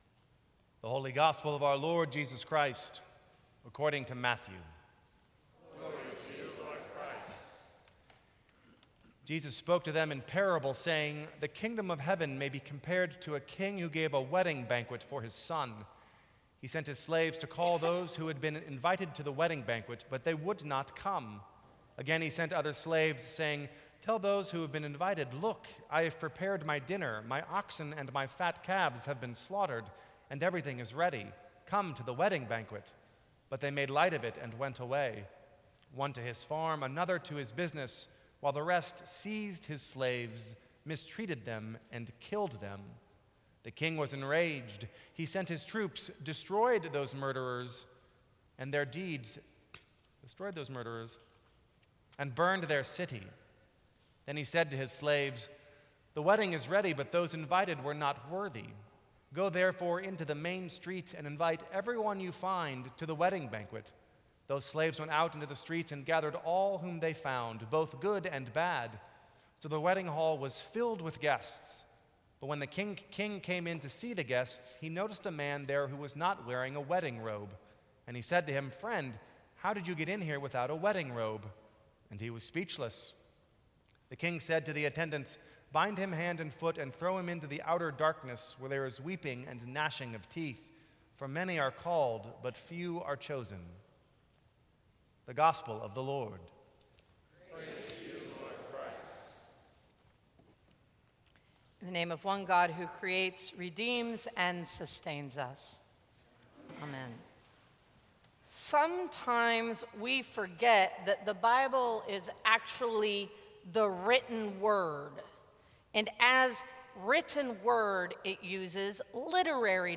Sermons from St. Cross Episcopal Church October 12, 2014.